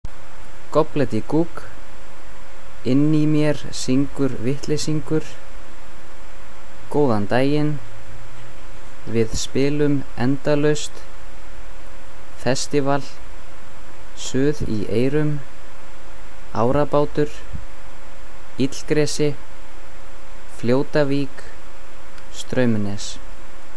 How to pronounce the album’s track names:
SR - track pronounce.mp3